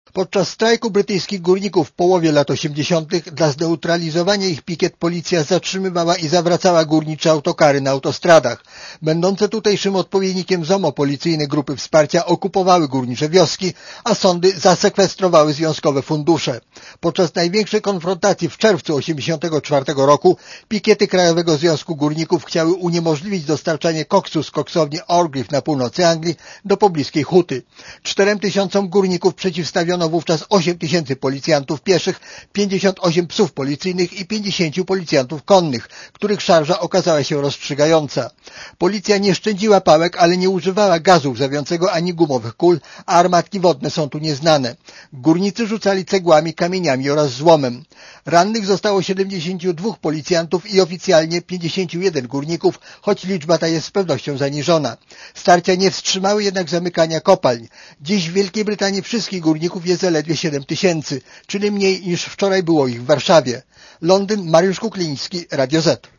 Posłuchaj relacji brytyjskiego korespondenta Radia Zet